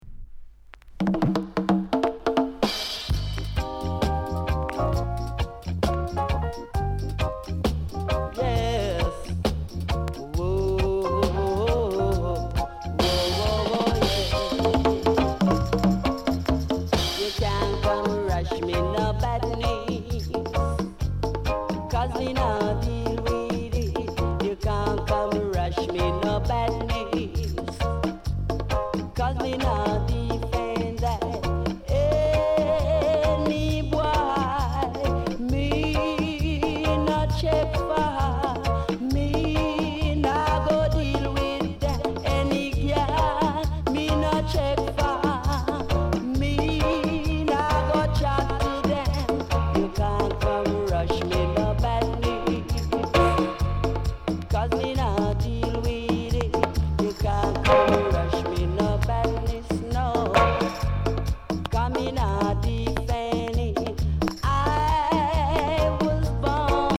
Sound Condition VG(OK)
STEPPER ROOTS